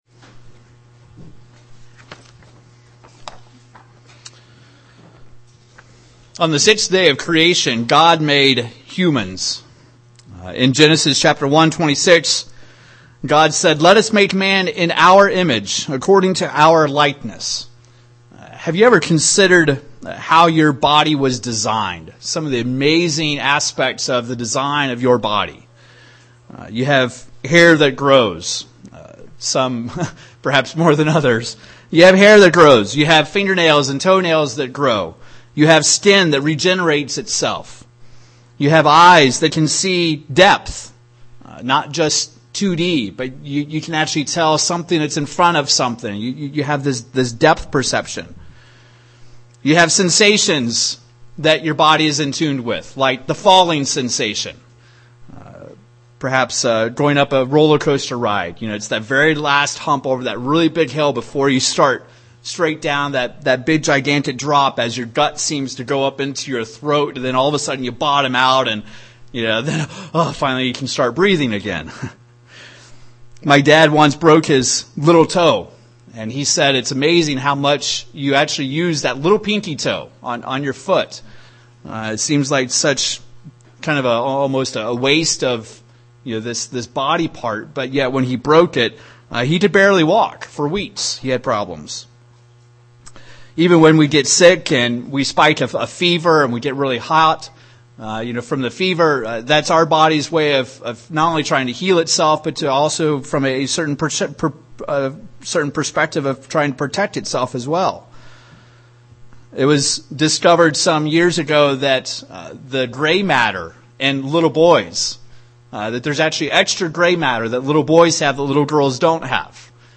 This sermon looks at the temperaments & personalities that God gave to us at the design of our body.